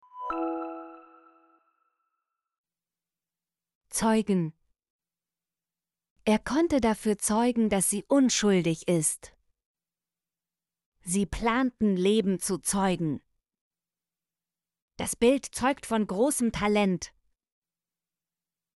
zeugen - Example Sentences & Pronunciation, German Frequency List